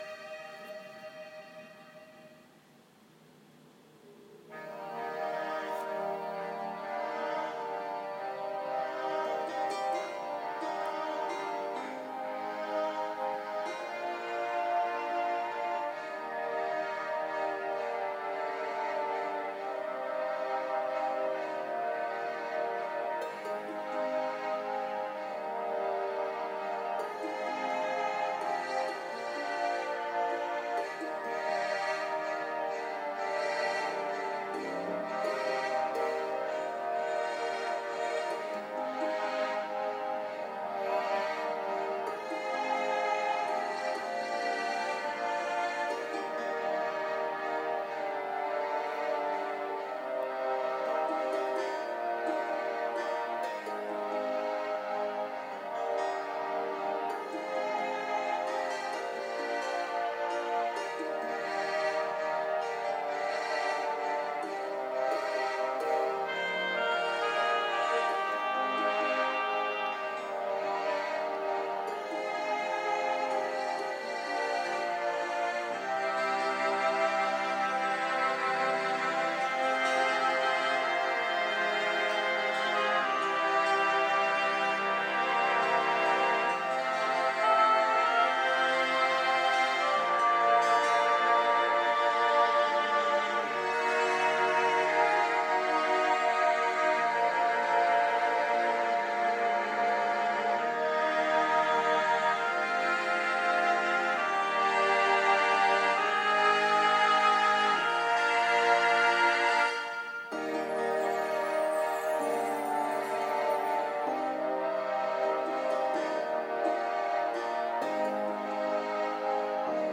There’s a small intro then you’re on: